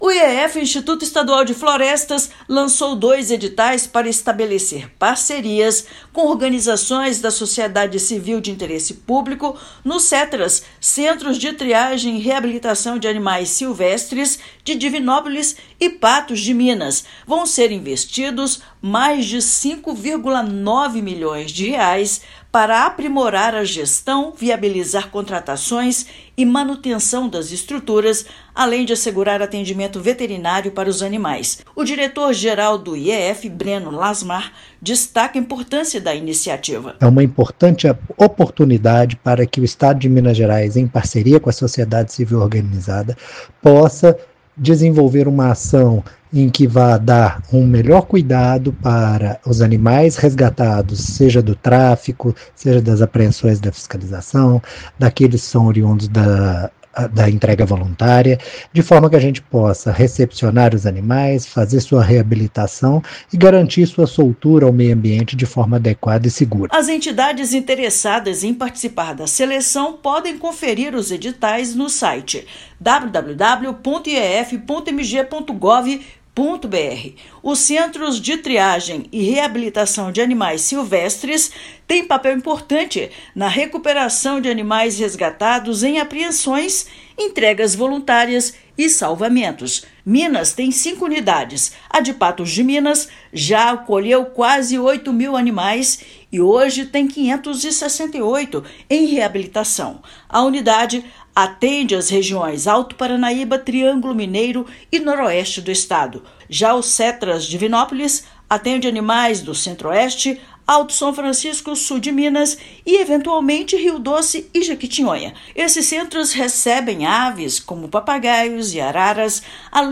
Editais somam mais de R$ 5,9 milhões para aprimorar a gestão, garantir manutenção das unidades e qualificar o atendimento veterinário. Ouça matéria de rádio.